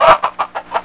CHICKEN.WAV